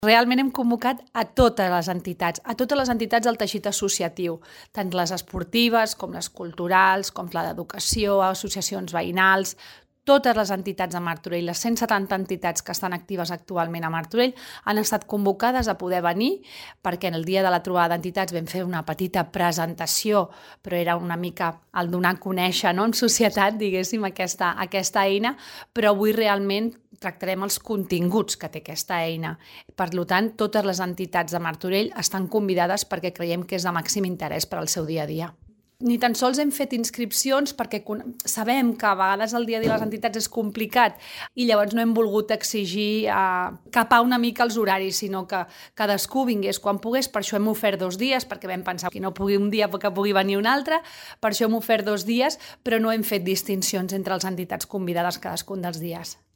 Àngels Soria, regidora de Teixit Associatiu